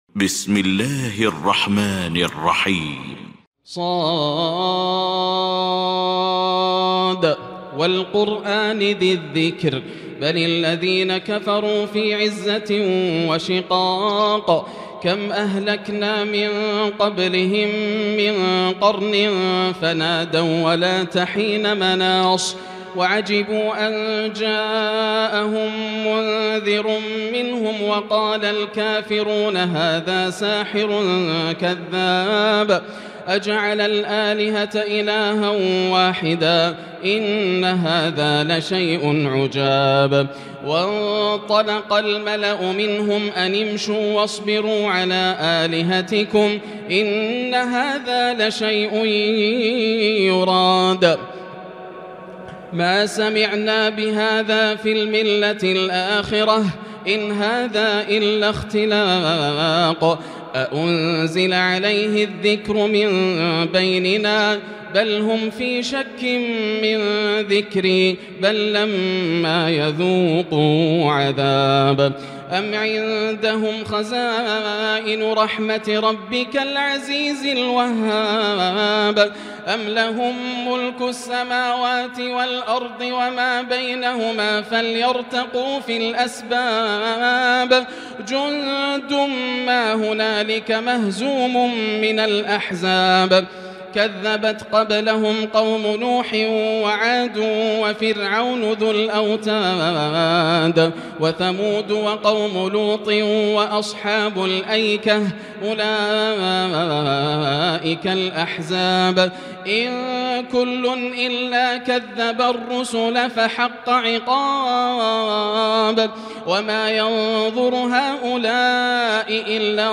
المكان: المسجد الحرام الشيخ: فضيلة الشيخ ياسر الدوسري فضيلة الشيخ ياسر الدوسري ص